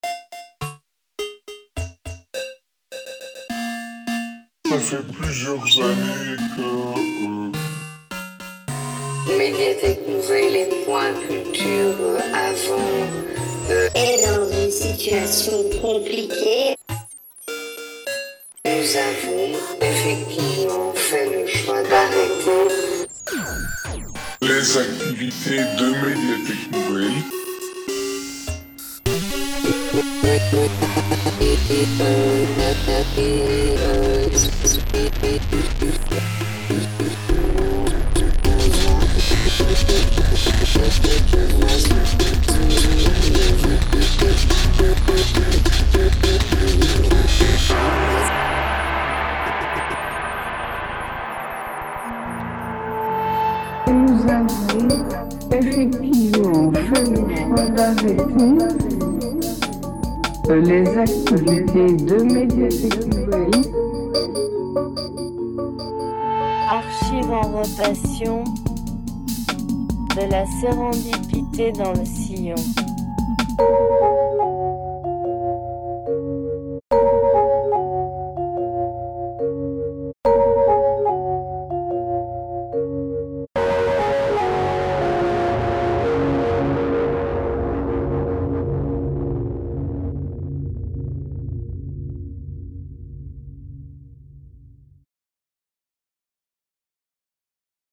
Collab pour Jingle Radio (dead line courte)
avec le coup de cymbale (?) qui traine a la fin effectivement c'est moins confuse :))) et le beat est plus calé il me semble